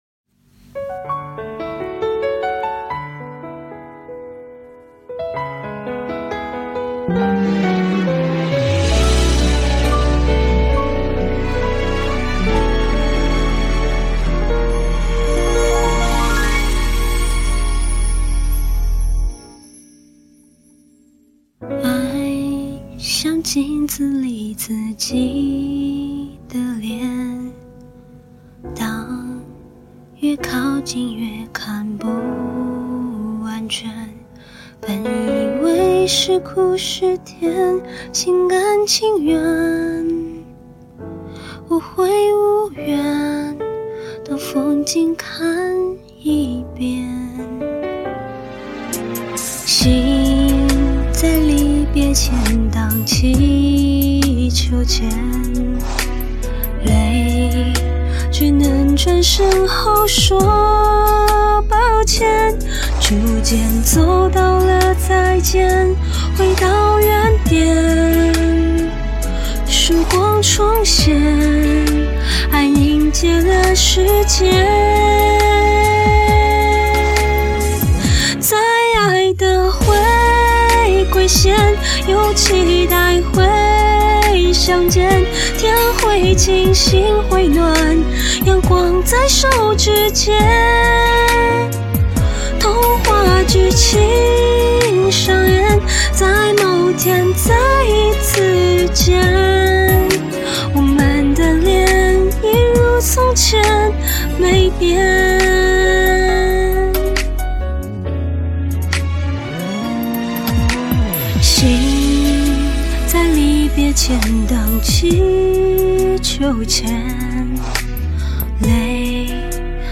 感冒了就要作死~
感冒了声音还这么好听？